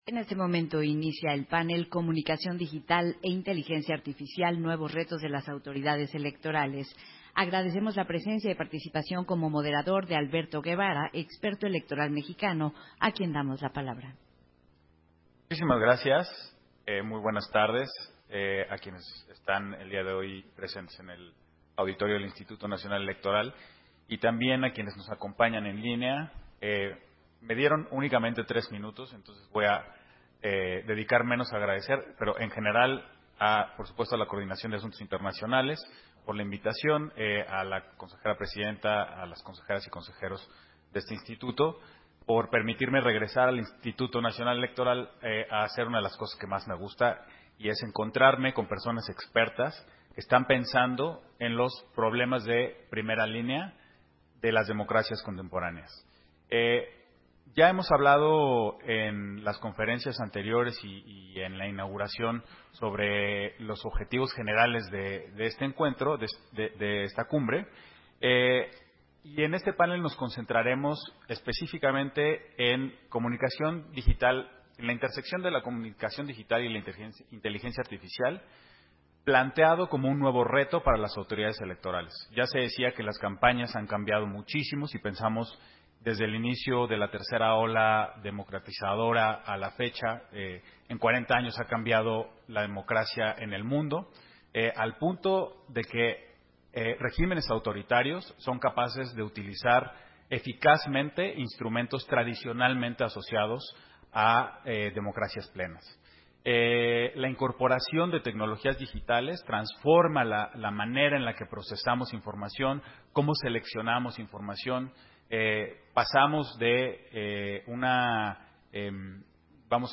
Versión estenográfica del panel, Comunicación digital e Inteligencia artificial: Nuevos retos de las autoridades electorales, en el marco de la II Cumbre de la Democracia Electoral